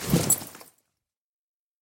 Sound / Minecraft / mob / horse / armor.ogg
armor.ogg